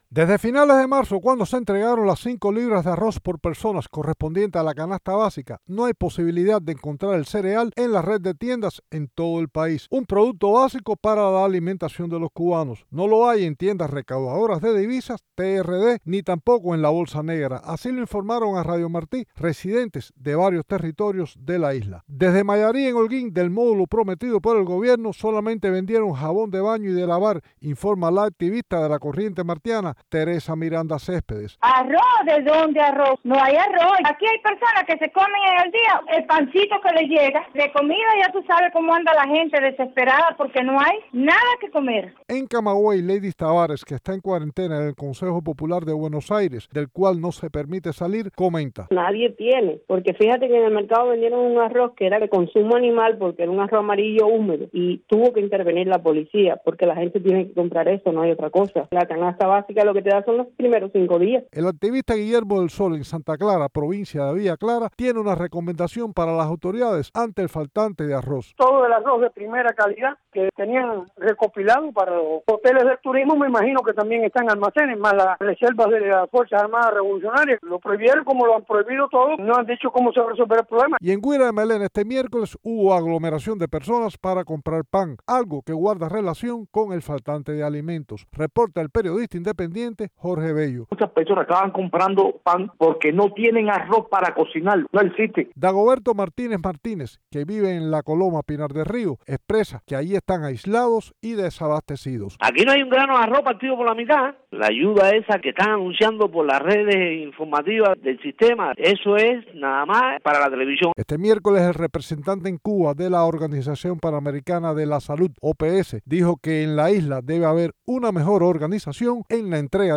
Cubanos de la isla comentan sobre escasez de alimentos